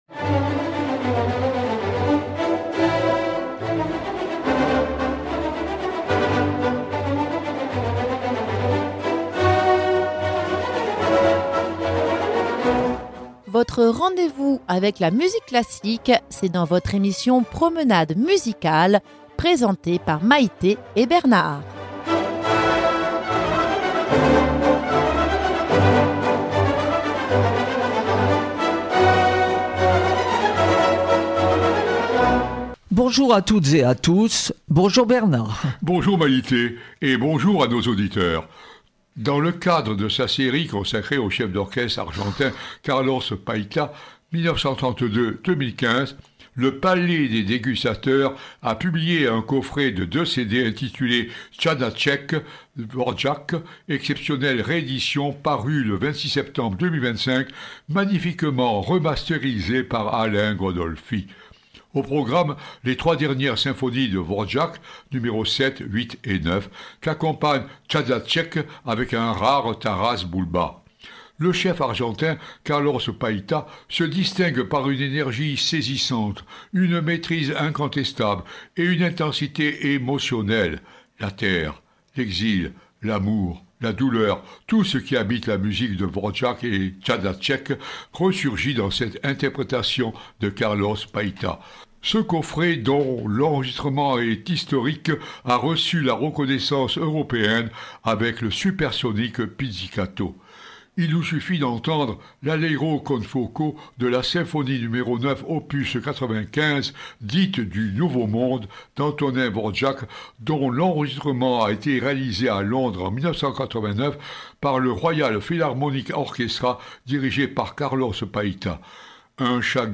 Symphonie
en mi mineur